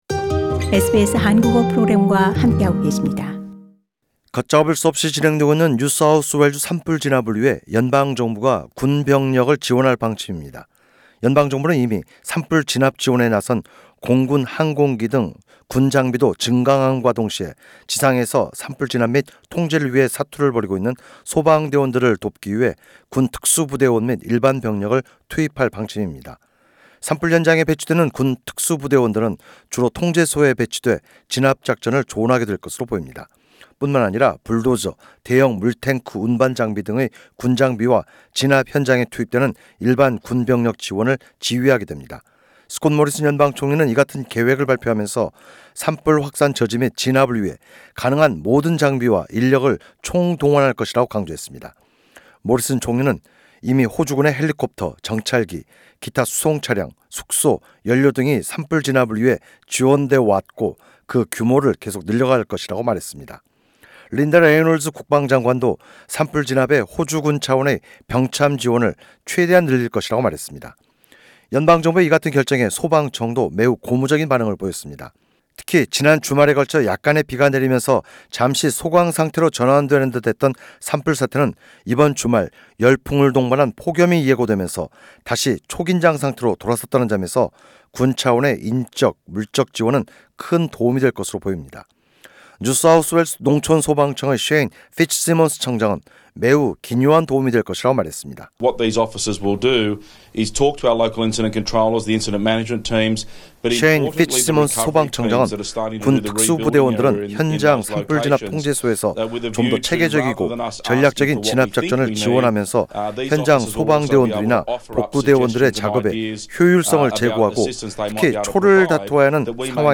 [상단의 팟캐스트를 통해 오디오 뉴스로 접하실 수 있습니다.]